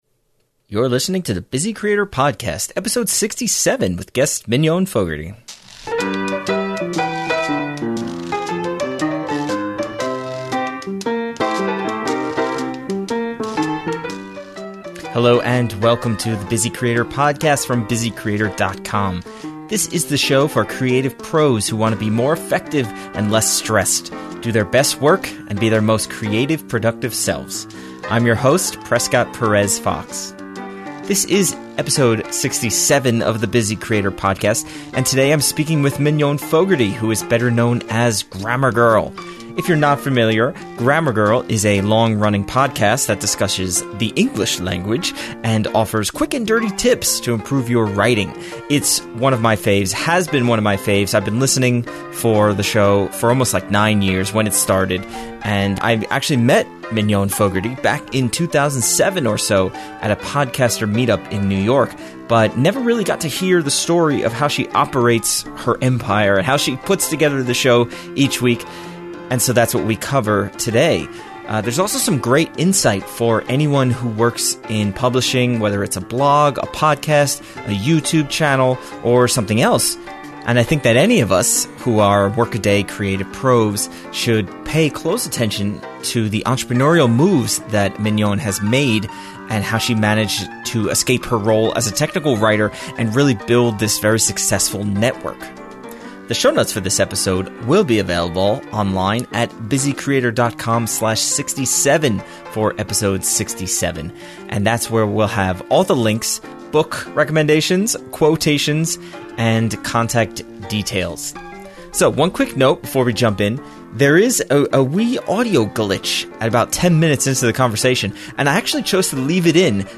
Our conversation follows Mignon’s origins in online business, how she leveraged her early podcast success and existing personal relationships to create a broader network, and how she still prefers to do certain production elements herself.